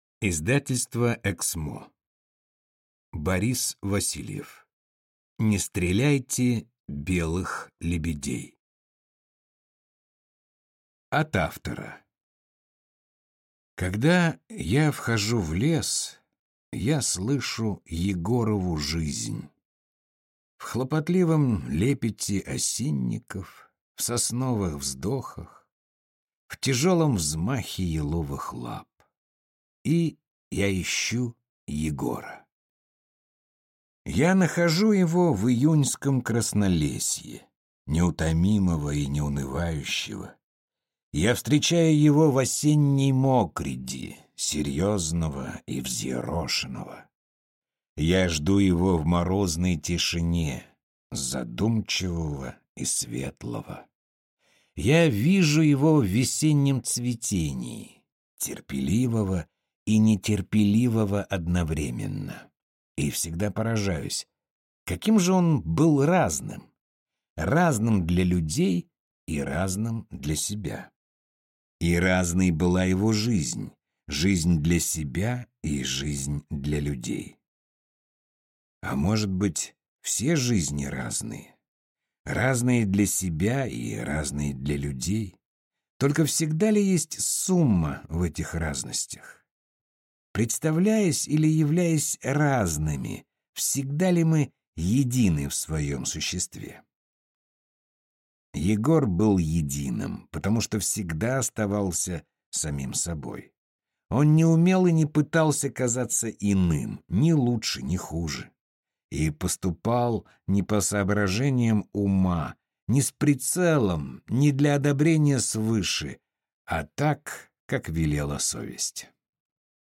Аудиокнига Не стреляйте белых лебедей | Библиотека аудиокниг